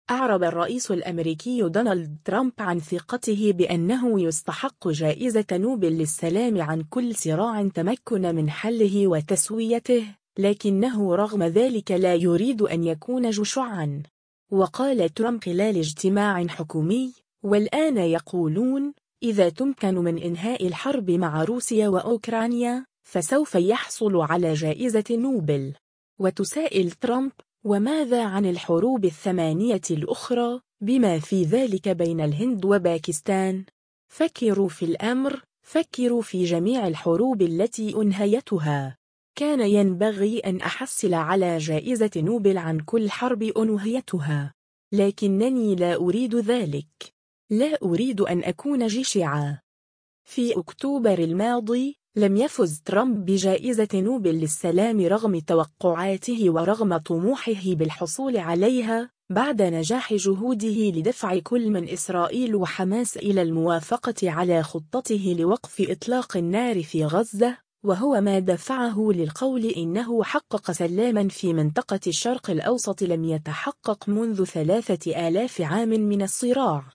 و قال ترامب خلال اجتماع حكومي : “والآن يقولون: إذا تمكن من إنهاء الحرب مع روسيا و أوكرانيا، فسوف يحصل على جائزة نوبل”.